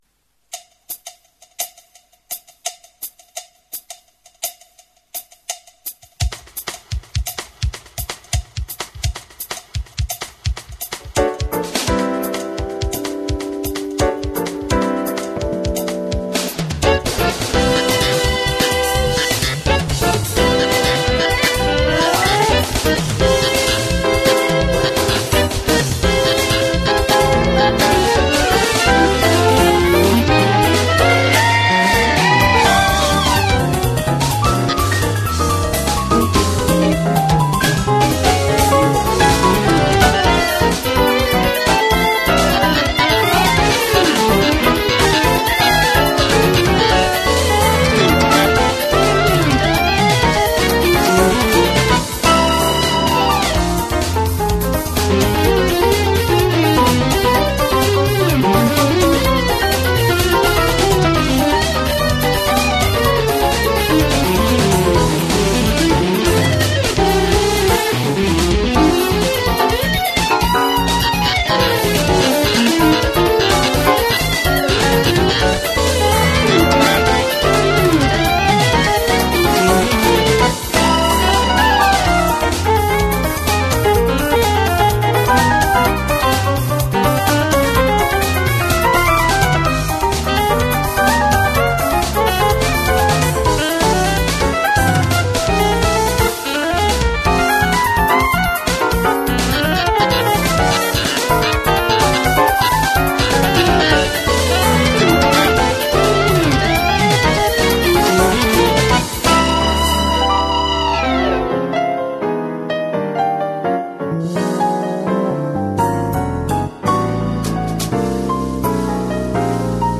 64kbps mono